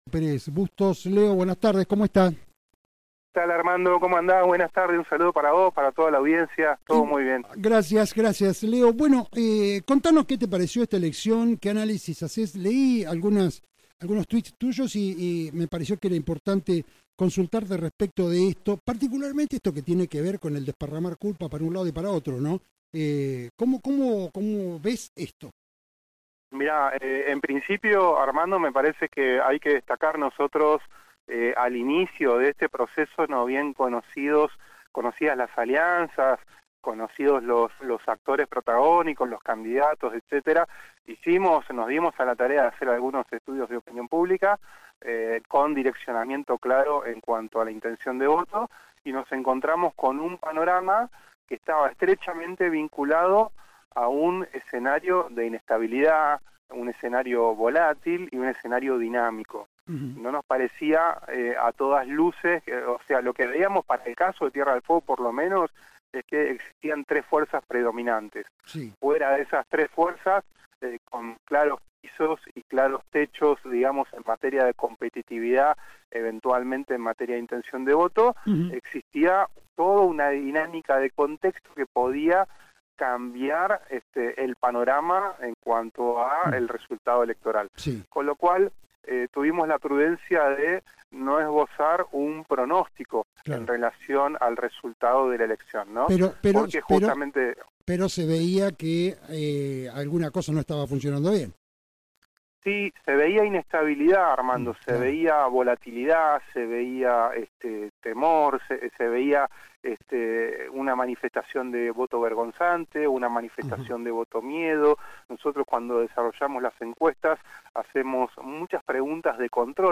en dialogo con Resumen Económico